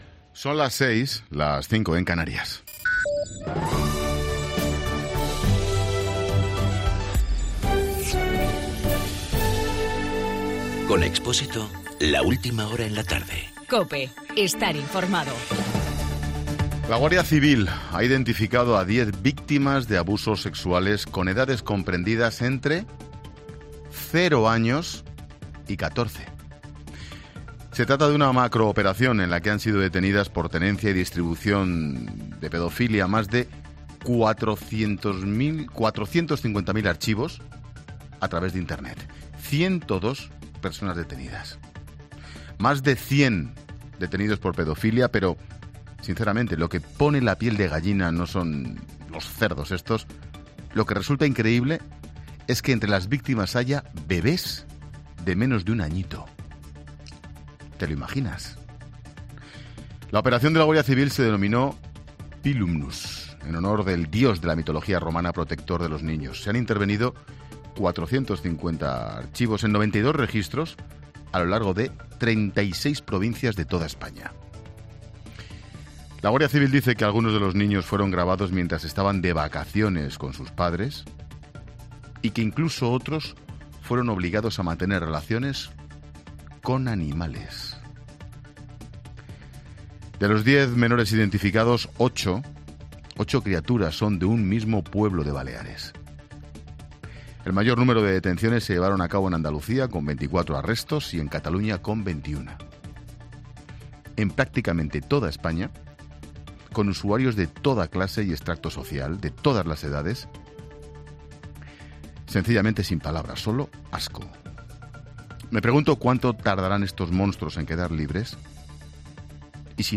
AUDIO: Monólogo 18 h.